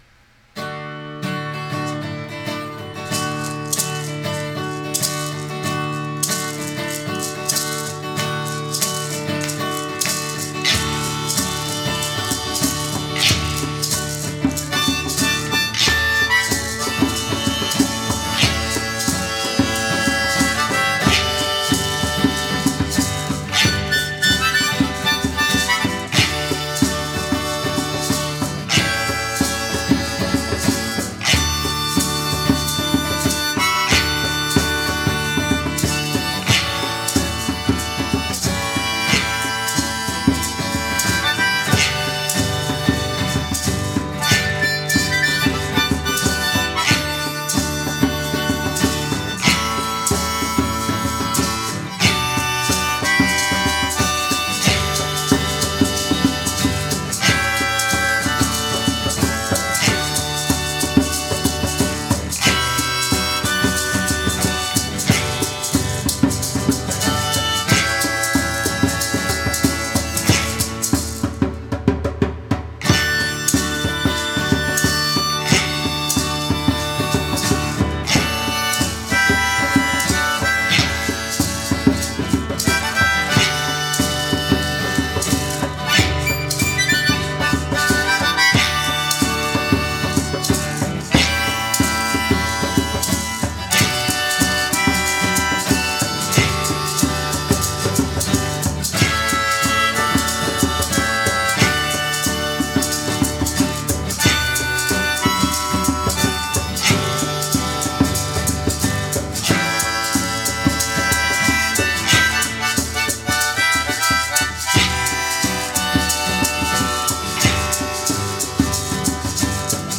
Don't mind the suboptimal sound quality in some of the songs, most of them are intentionally not polished.
Idea here was to create a short song so that every one could play something in it.
This is recorded by just putting one mic in the room and there we go...